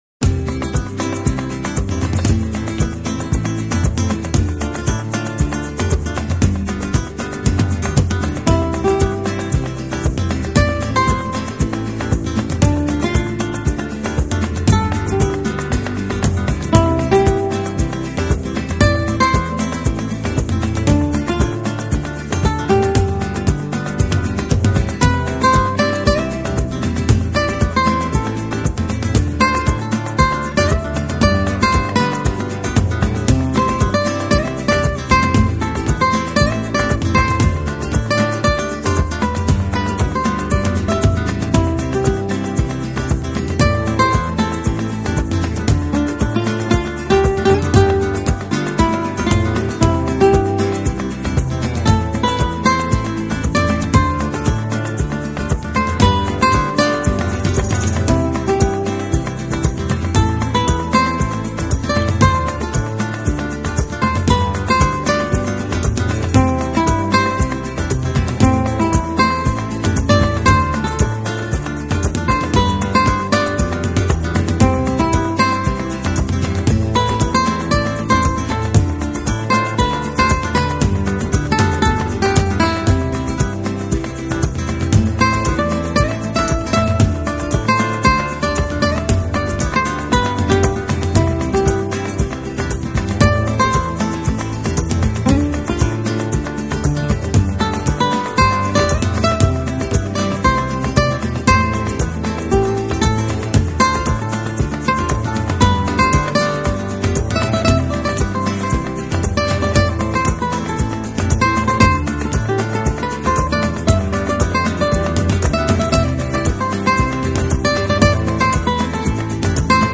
【吉他专辑】
乐队编排的那种演奏形式，融入了许多流行、爵士和吉普赛音乐等元素；但是，这张唱
拨，击等指法也清晰可见；乐队中不时出现的击掌，敲击之声更；是立体至极，每一件